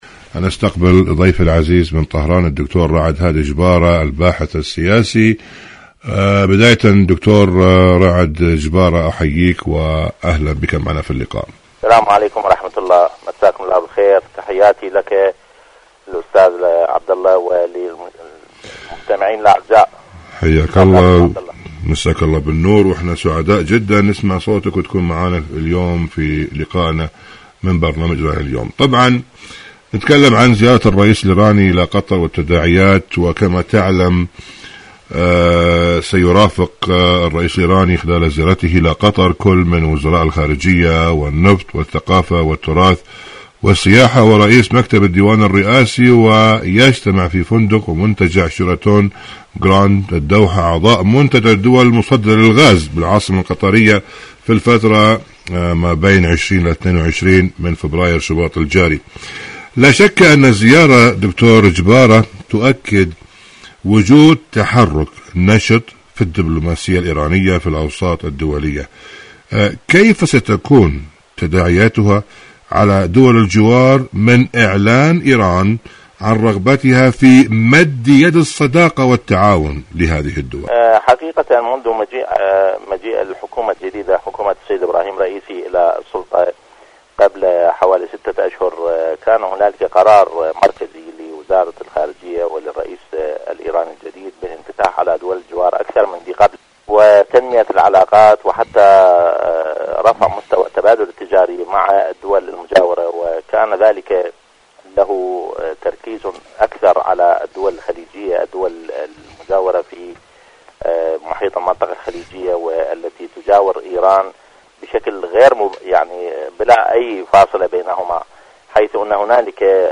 إذاعة طهران-إيران اليوم المشهد السياسي: مقابلة إذاعية